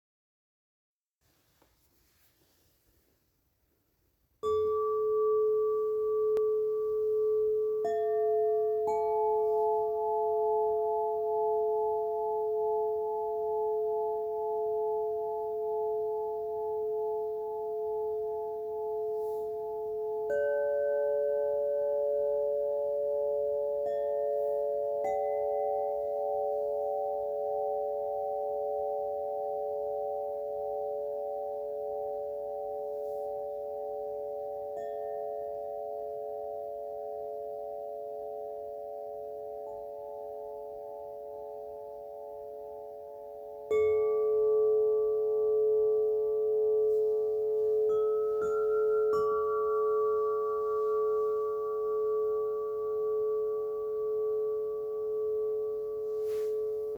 Neu: Livemusikeinlagen –  zur Einstimmung musizieren wir am Klavier, auf der Kailani oder auf der Handpan und die wohltuenden Schwingungen wirken unmittelbar auf dein System –